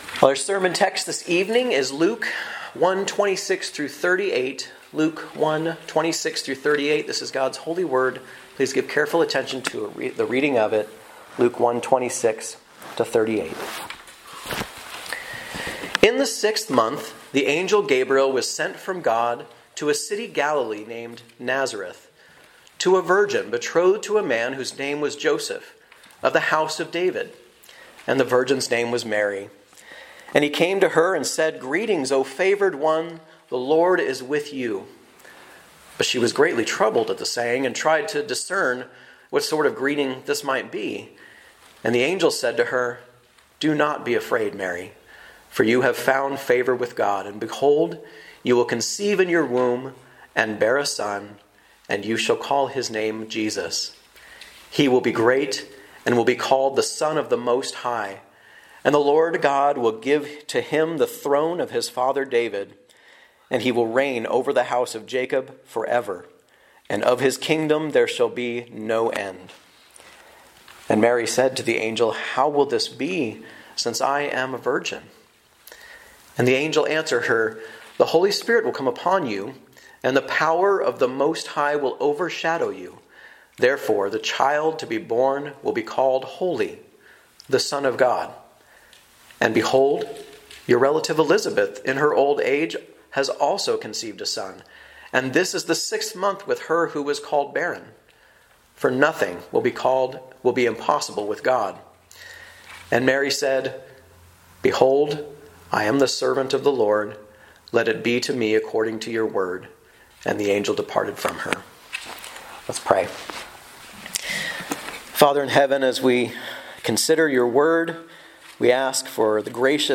From Series: "Guest Preacher"